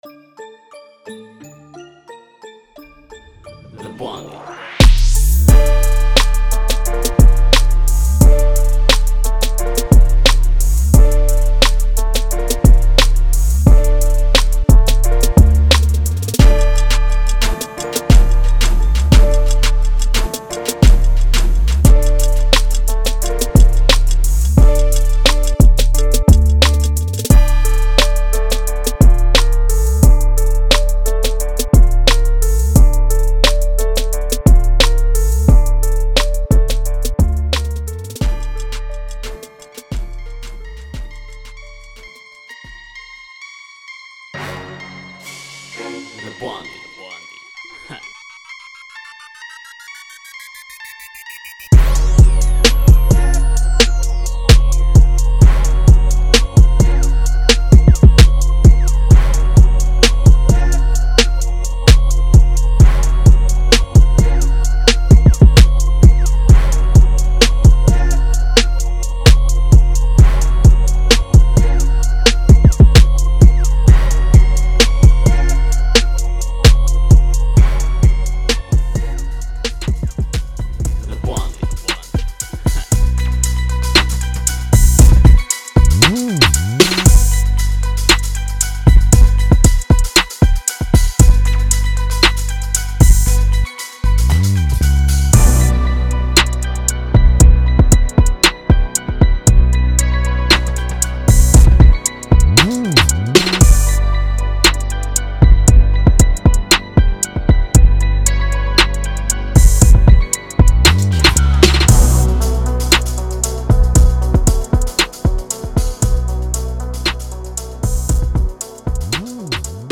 Hip HopTrap